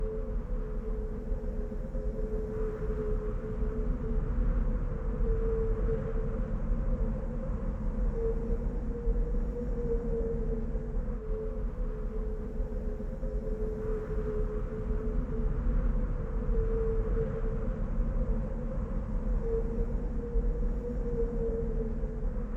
traffic light: loop wind sound without gaps
atmosphere.opus